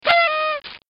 Party Horn sound